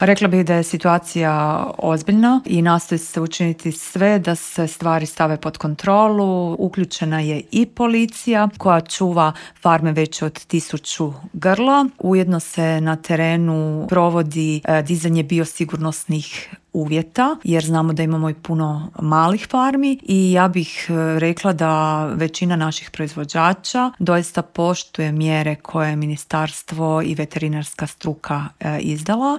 U popodnevnim satima sastaje se i saborski Odbor za poljoprivredu čija je predsjednica Marijana Petir gostovala u Intervjuu Media servisa: